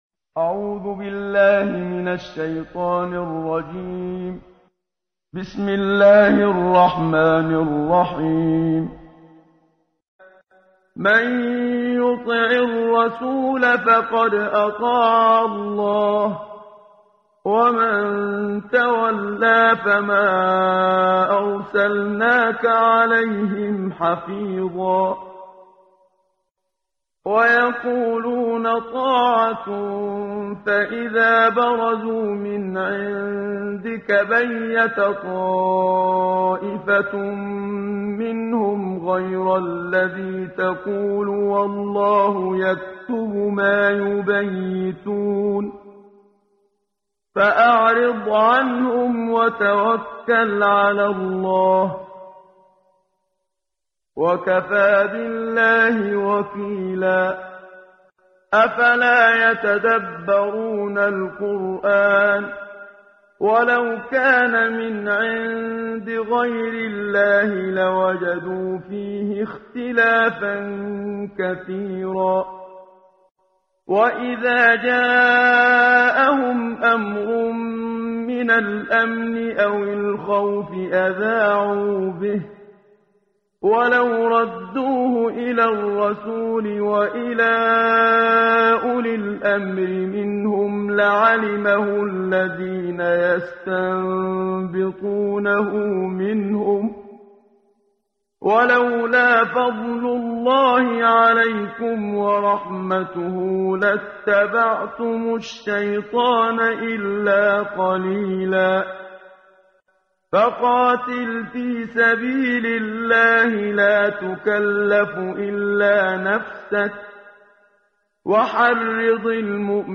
قرائت قرآن کریم ، صفحه 91 ، سوره مبارکه نساء آیه 80 تا 86 با صدای استاد صدیق منشاوی.